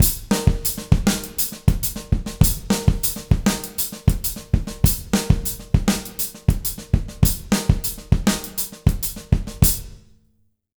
100SONGO02-R.wav